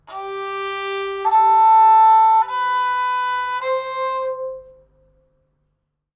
Sounds on strings in Qeychak are like this:
1st string in 1st position: